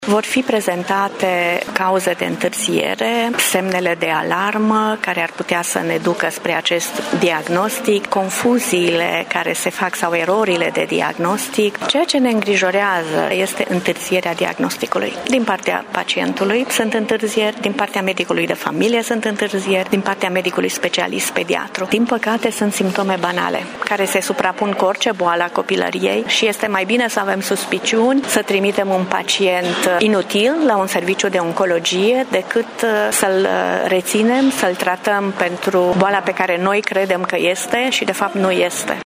Medicul specialist hemato-oncolog din Tîrgu-Mureș